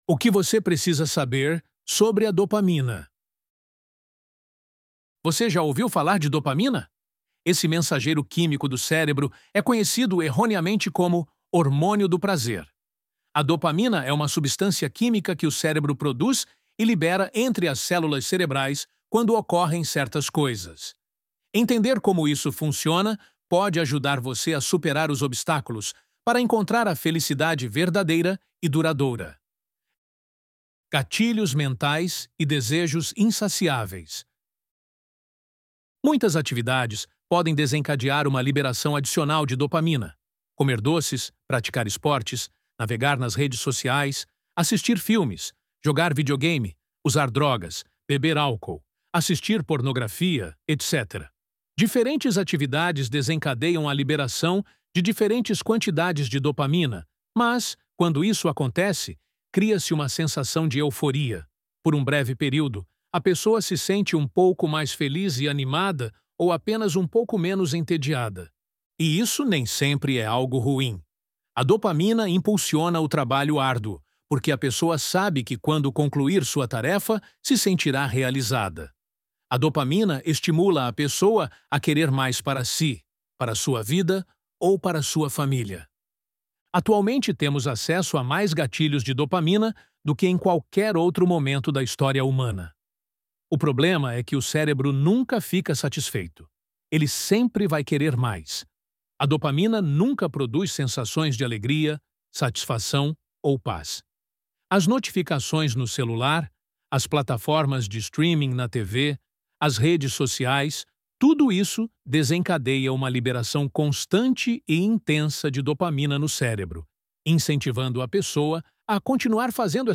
ElevenLabs_O_Que_Você_Precisa_Saber_Sobre_a_Dopamina.mp3